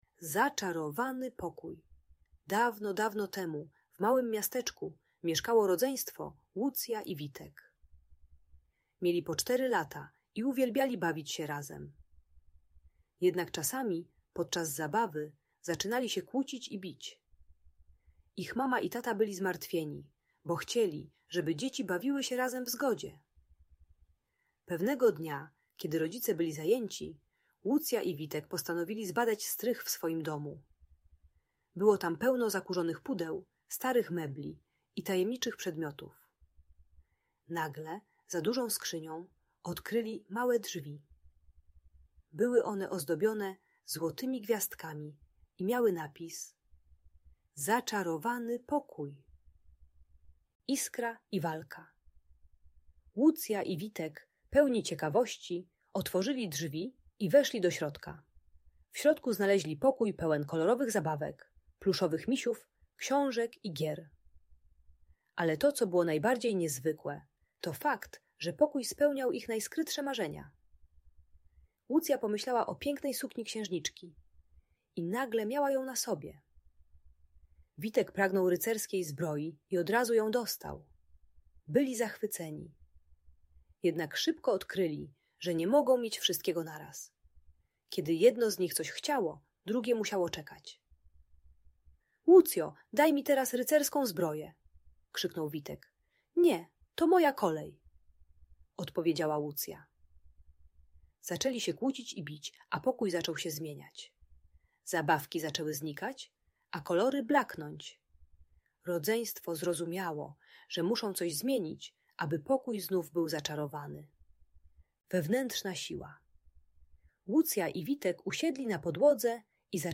Zaczarowany Pokój - Rodzeństwo | Audiobajka